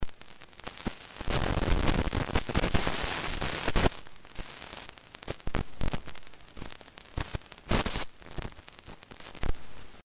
Шум в в звуковом входе у аудиокарты
Когда купил, шум был тихим но был, сейчас еще громче стал!что может быть ? вход менять ? или что то другое?